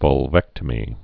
(vŭl-vĕktə-mē)